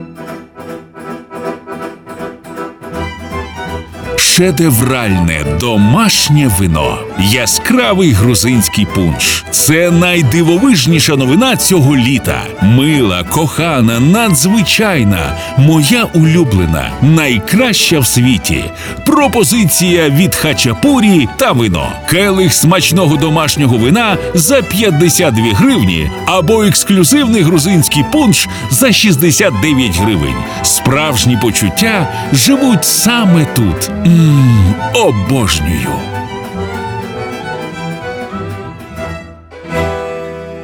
FireBrands – експерти зі звукового дизайну для радіо- і TV-реклами.